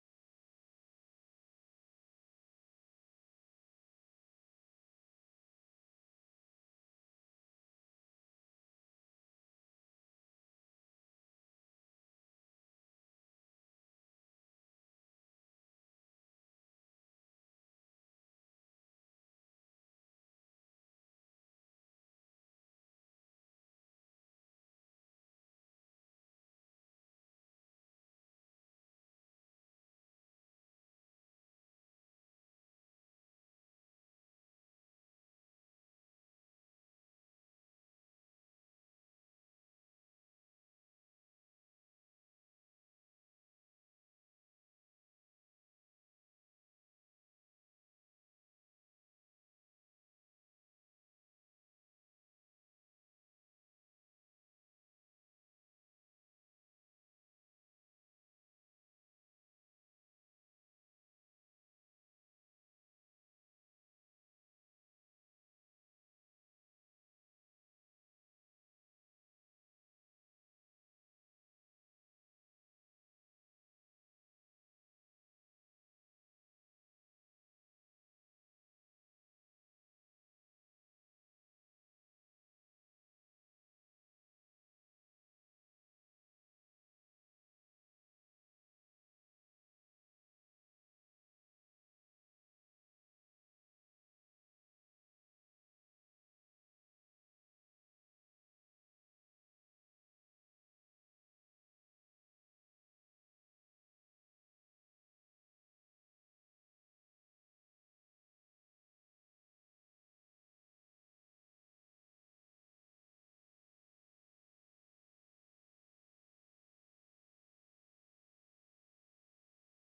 Responsibility-Unity-Sermon-Audio-CD.mp3